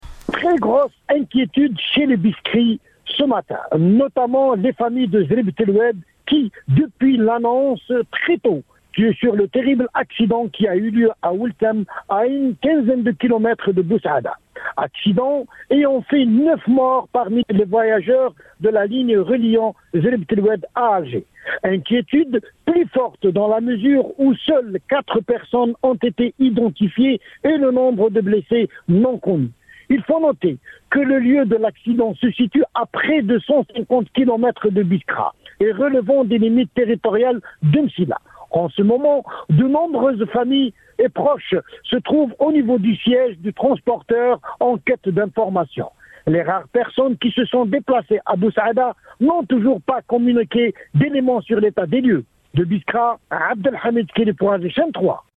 le compte rendu